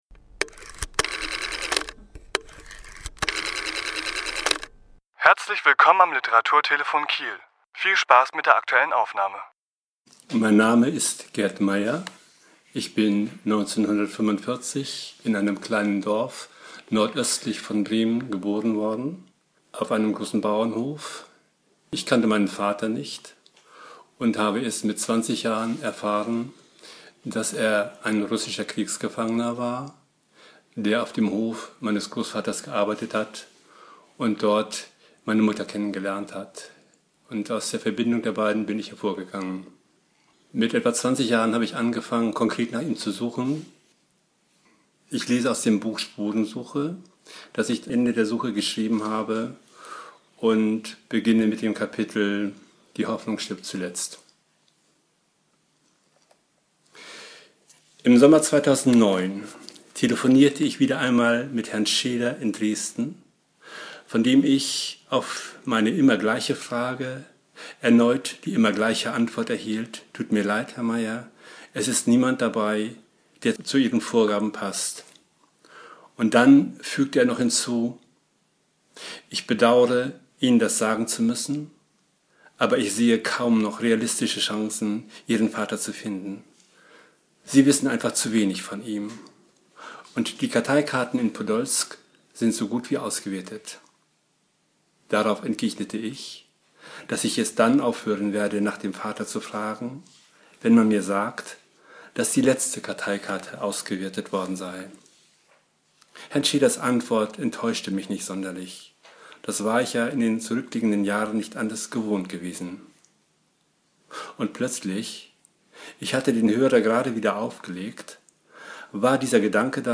Autor*innen lesen aus ihren Werken
Die Aufnahme entstand im Rahmen einer Lesung in der Förde-VHS Kiel am 6.4.2017.